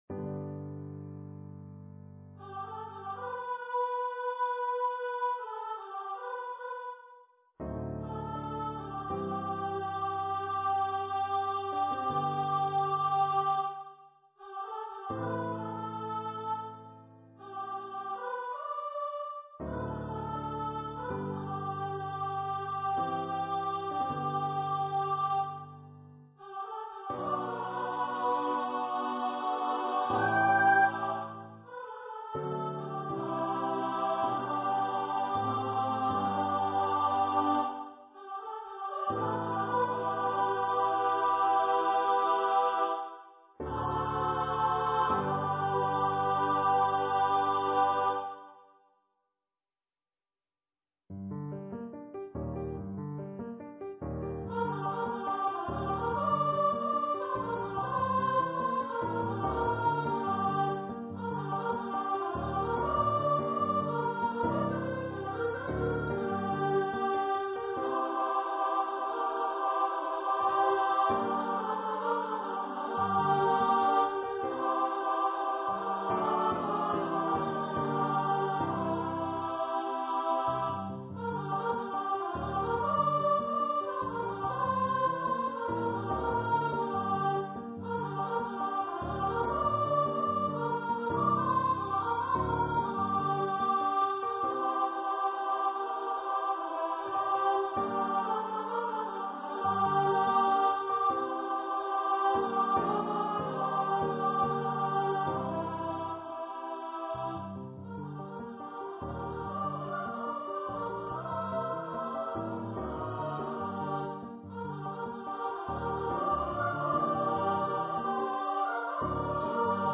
for female voice choir and piano
Choir - 3 part upper voices